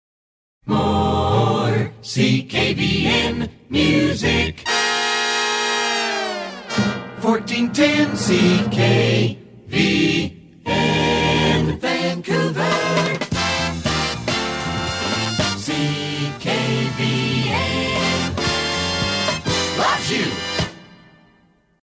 Jingle Montages Courtesy of